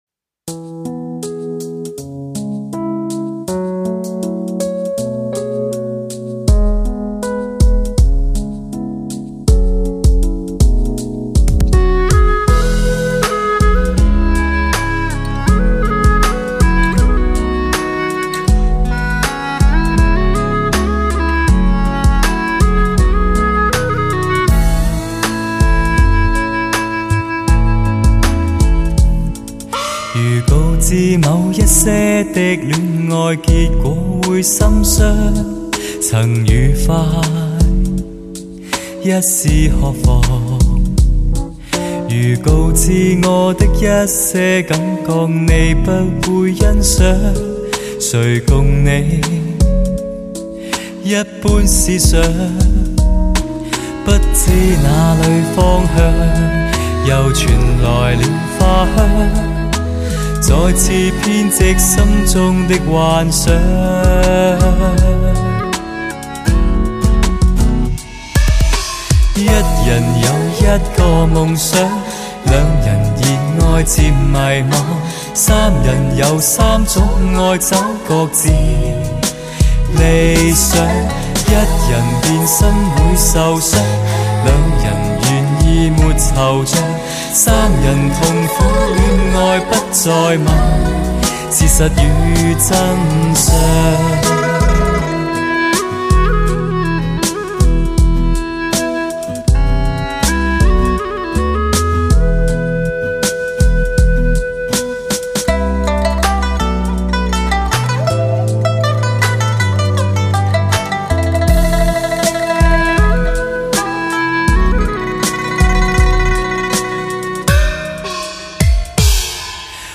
HIFI人声高解析 高临场天碟
类型: 汽车音乐
SOUND 专业天碟，专有STS Magix 母带制作，STS magix virtual live高临场感CD。